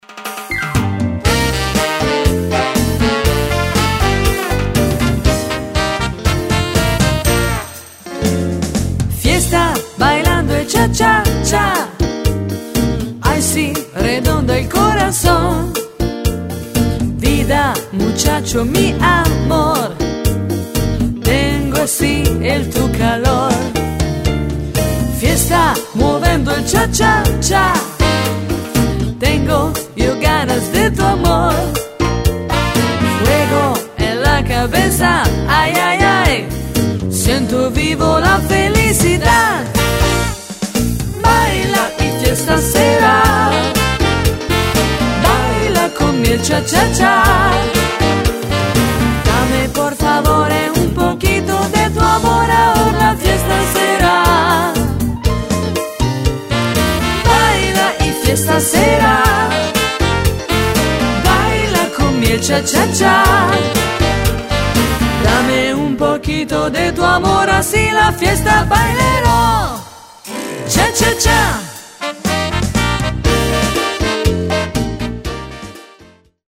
Cha cha cha
Donna